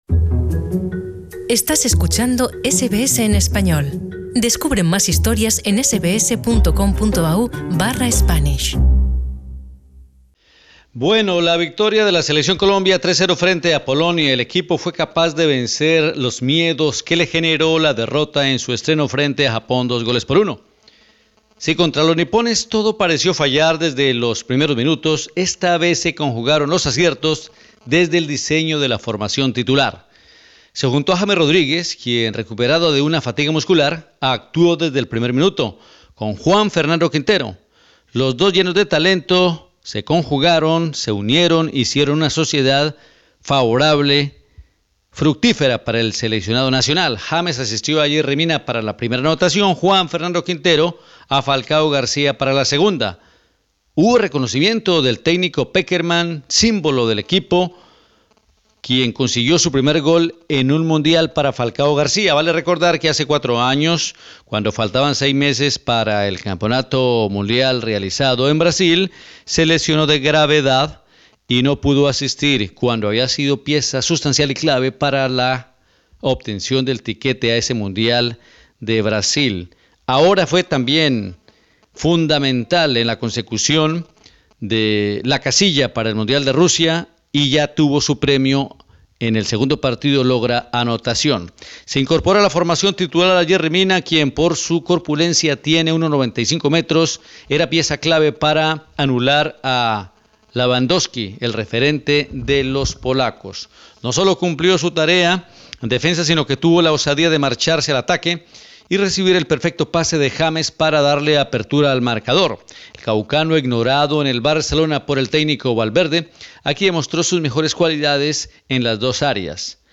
Escucha la crónica de LO BUENO, LO MALO Y LO FEO